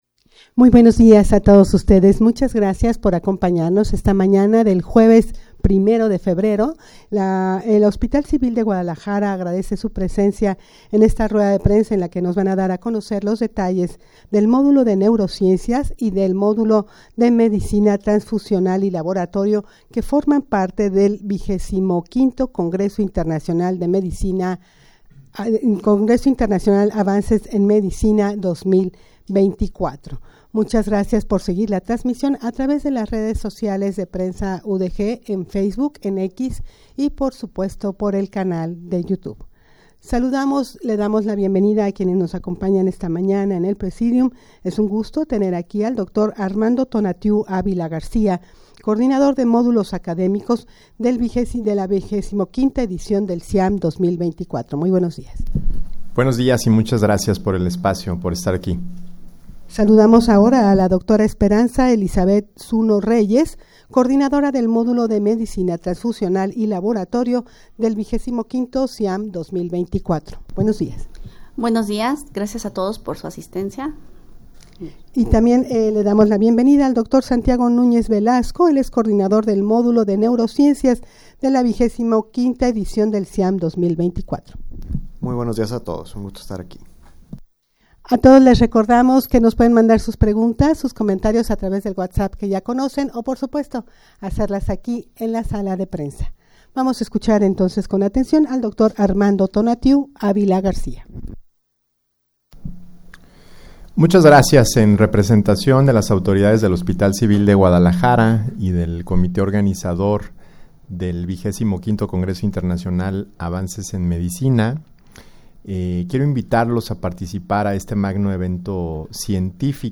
Audio de la Rueda de Prensa
rueda-de-prensa-para-dar-a-conocer-los-detalles-del-modulo-de-neurociencias-y-medicina-transfusional-y-laboratorio.mp3